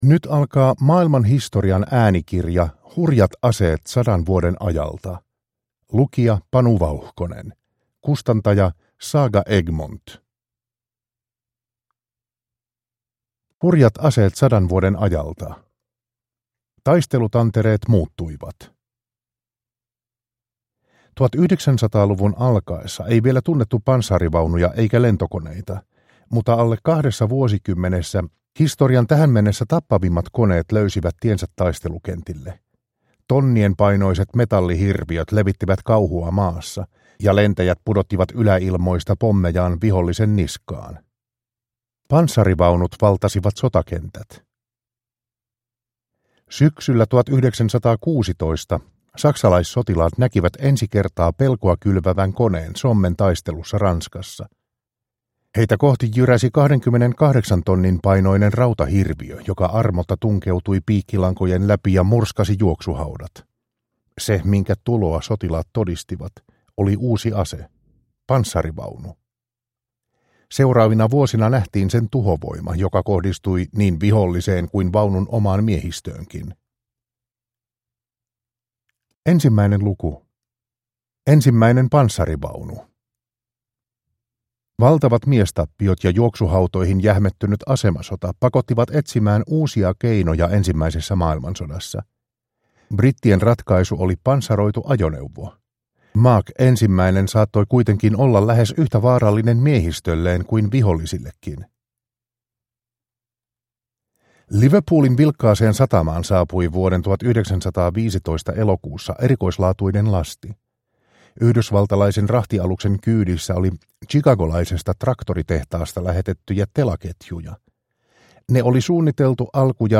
Hurjat aseet 100 vuoden ajalta – Ljudbok